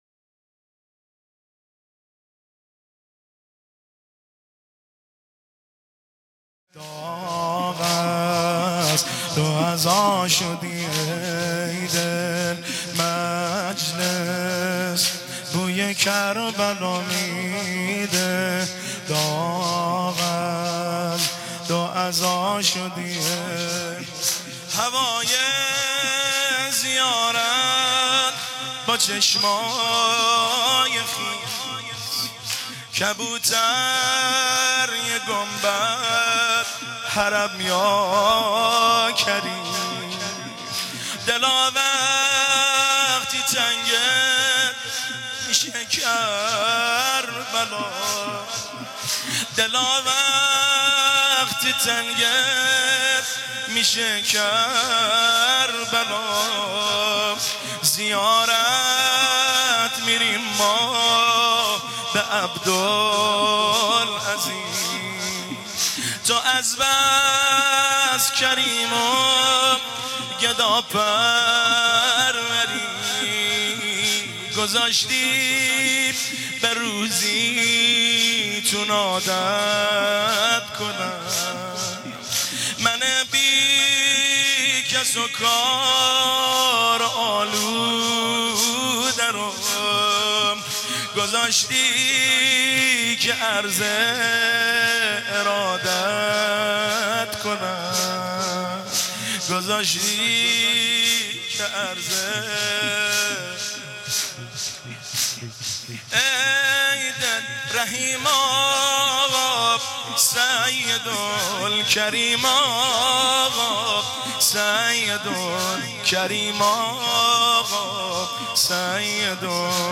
صوت مداحی وفات حضرت عبدالعظیم حسنی علیه السلام